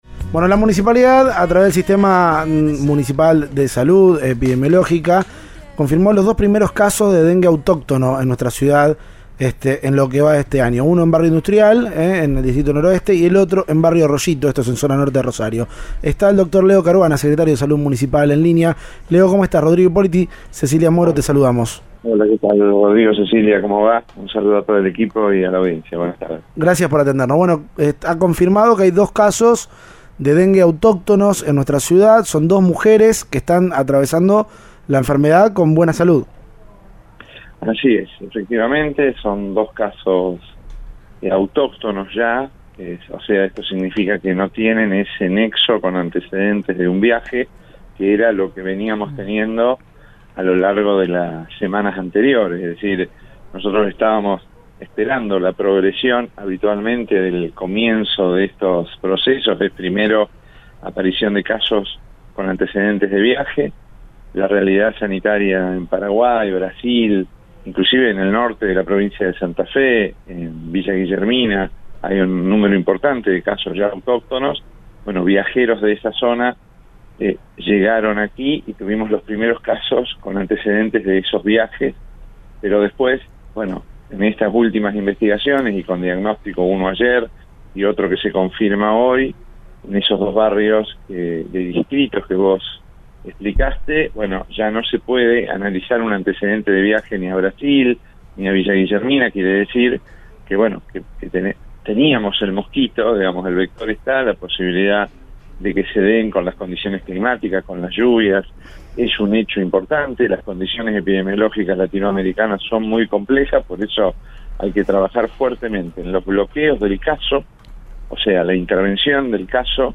El secretario de Salud Pública de la Municipalidad, Leonardo Caruana, habló en Cadena 3 Rosario sobre el avance de los casos en la ciudad.
El secretario de Salud de Rosario, Leonardo Caruana, habló sobre los casos de dengue.